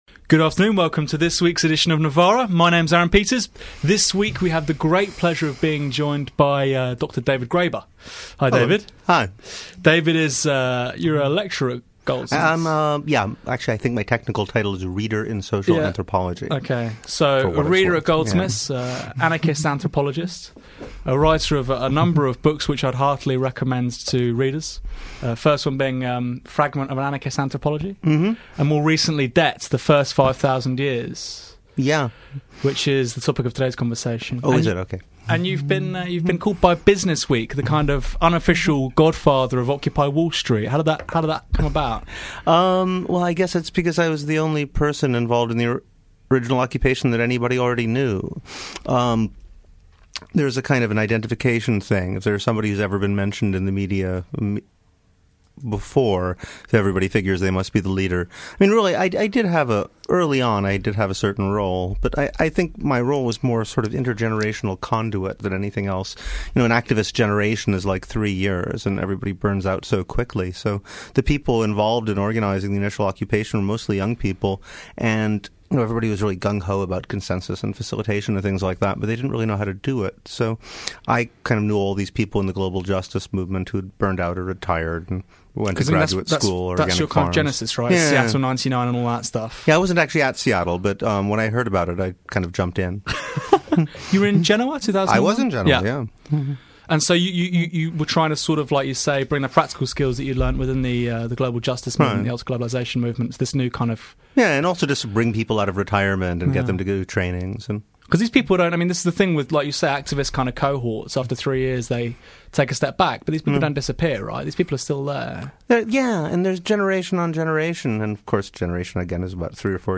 Novara Media Interview about debt
Aaron Bastani is joined by David Graeber author of ‘Debt: the First 5,000 Years’ as they discuss debt embedding it within the context of the Great Recession.
Novara-Interview-With-David-Graeber.mp3